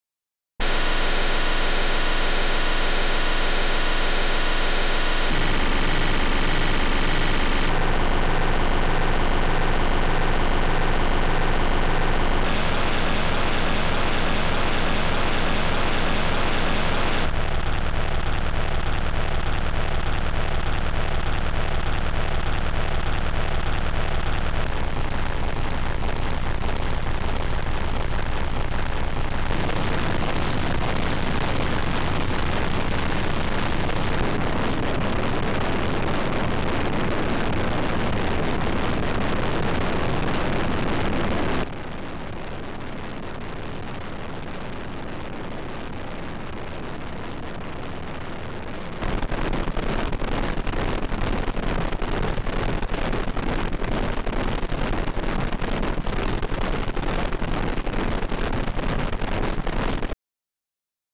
Generic Additive Synthesis
t = Steno.push(8); // a small 8 channel spectrum
Generic additive synthesis is a generalisation of additive sound synthesis.